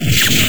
[A] Blade meets flesh sound
Ripped from Gyakuten Saiban 3 with additional modifications (such as noise removal) done in Audacity.